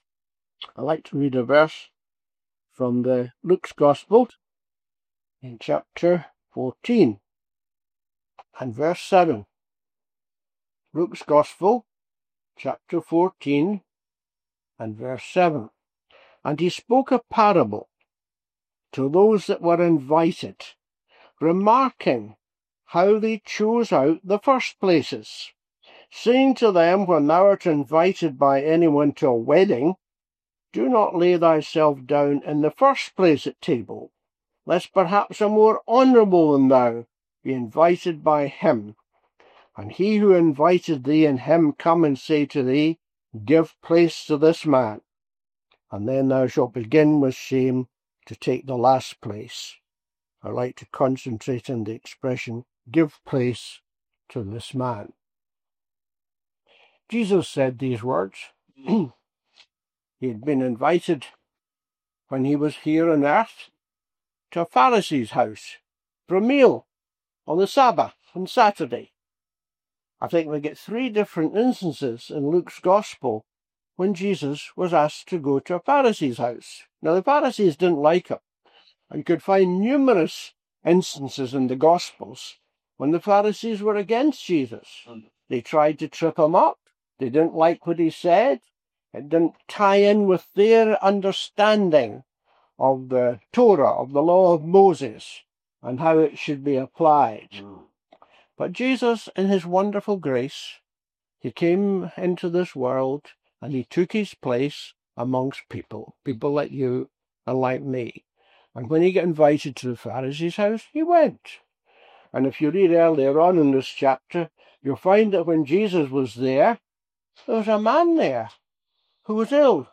In this stirring Gospel message, we explore the powerful moment in Luke 14:9 where Jesus tells of a guest being asked to “give place” to another. This preaching calls us to make room for Christ in every area of our lives, and honour the One who deserves the highest seat.